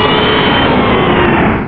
pokeemerald / sound / direct_sound_samples / cries / kyogre.aif
-Replaced the Gen. 1 to 3 cries with BW2 rips.